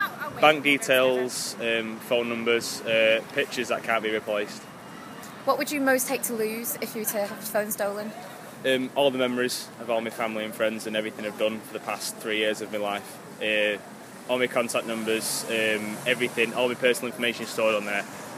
We asked a passer-by in Manchester City Centre what he keeps on his phone and what he'd be most upset about losing if it was stolen.